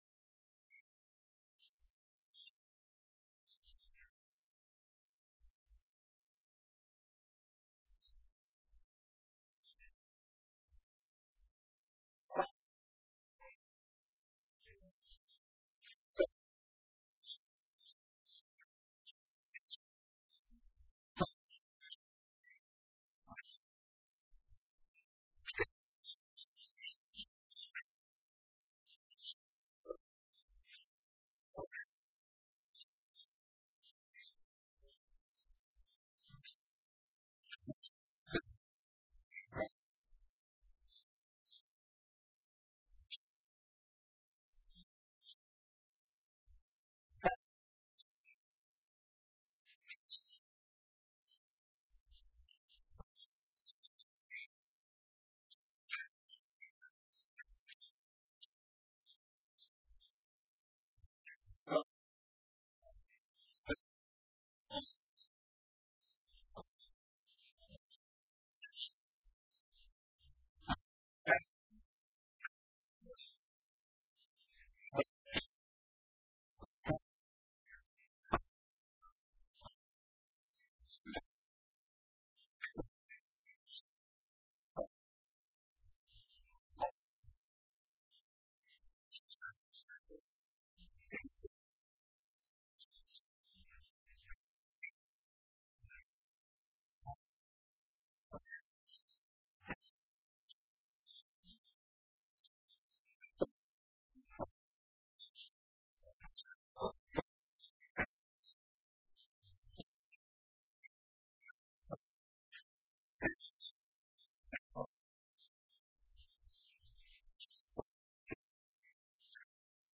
Last Sermon